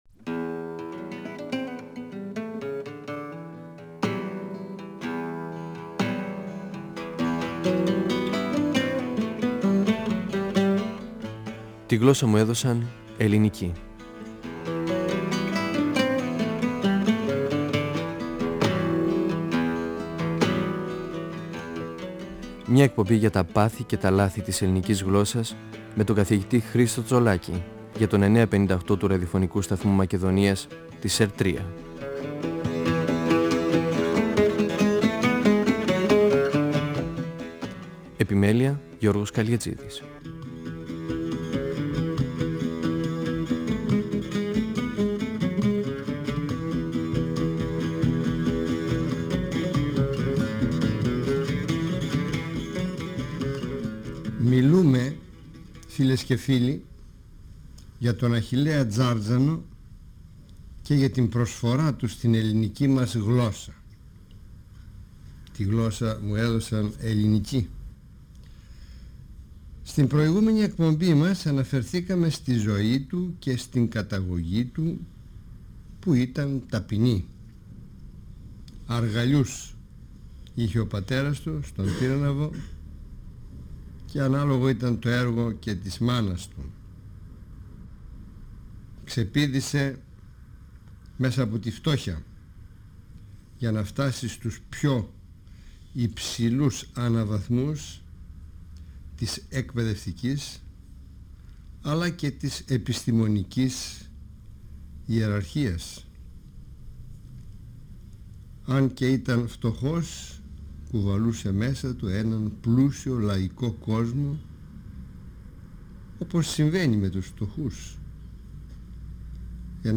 Ο γλωσσολόγος Χρίστος Τσολάκης (1935-2012) μιλά για τη συμβολή του Αχιλλέα Τζάρτζανου (1873-1946) στα θέματα της ελληνικής γλώσσας.